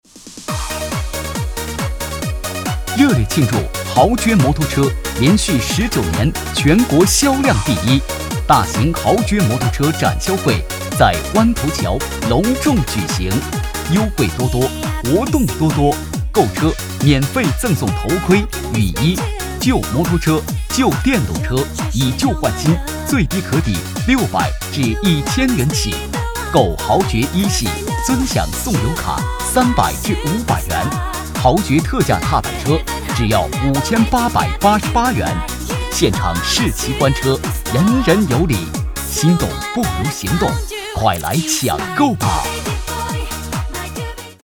男46号配音师 点击进入配音演员介绍 进入后下滑播放作品集↓↓↓ 配音演员自我介绍 B级配音师，2008年毕业于成都理工大学广播影视学院 播音与主持艺术系 本科 2012年取得中国传媒大学MFA艺术硕士学位 拥有播音教学10年经验 从事配音行业8年 声音 庄重 自然 能表现真实 老师宣言:音尚生活，精彩世界. 代表作品 Nice voices 促销 广告 专题片 方言 促销-男46-海尔专卖店（激情）.mp3 复制链接 下载 促销-男46-爱玛电动车（喜悦）.mp3 复制链接 下载 促销-男46-豪爵摩托（年轻）.mp3 复制链接 下载